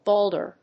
/ˈbɔldɝ(米国英語), ˈbɔ:ldɜ:(英国英語)/